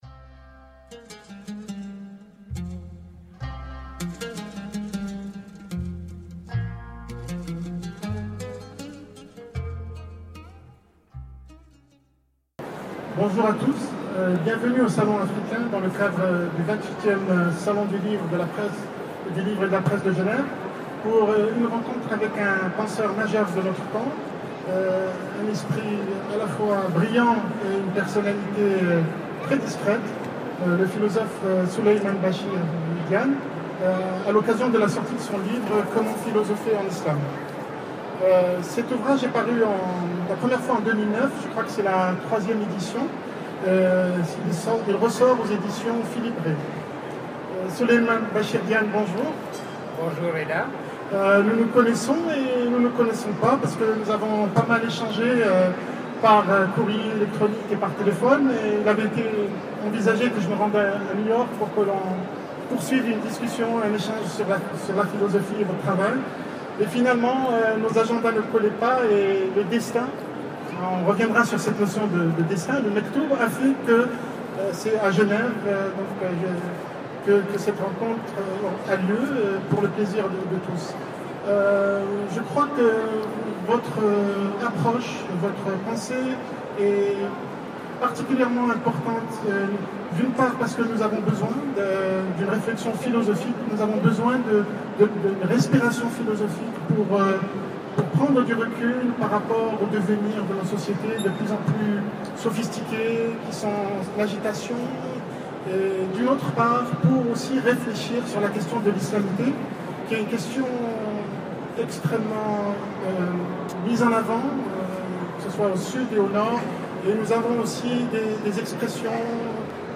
Un philosophe de la traduction. Conversation avec Souleymane Bachir Diagne
au Salon africain, Salon du livre et de la presse de Genève